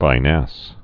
(vī-năs, vĭ-)